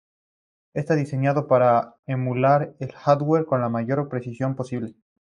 Read more to emulate Hyphenated as e‧mu‧lar Pronounced as (IPA) /emuˈlaɾ/ Etymology From Latin aemulārī.